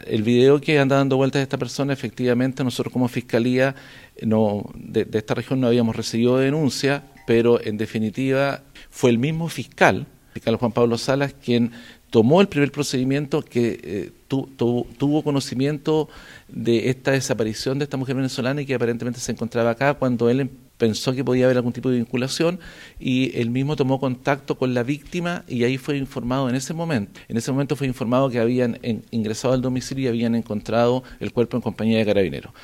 Sobre el hecho, también se refirió el fiscal regional subrogante Alberto Chifelle.